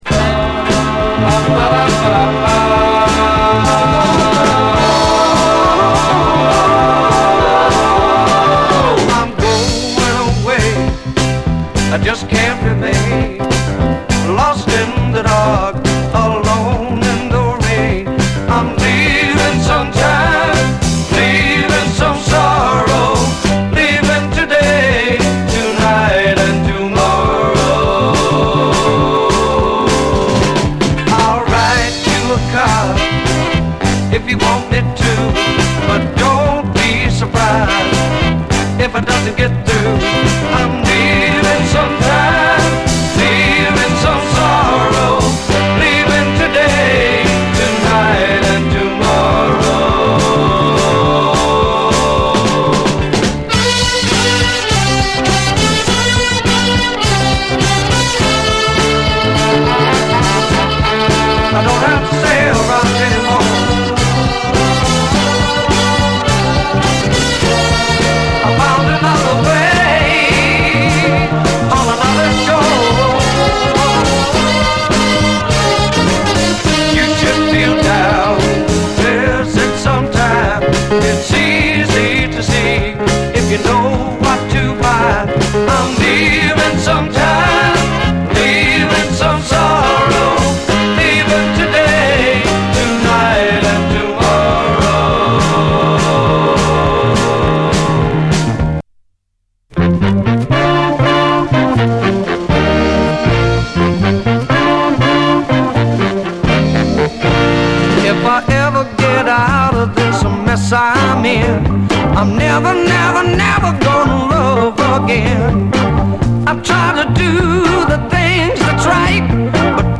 60's SOUL, SOUL, 7INCH
ブルーアイド・ノーザン・ソウル45！
ソフト・ロック的なコーラス・ハーモニーのヤング・モッド・ソウル
哀愁系ミッド・ノーザン・ダンサー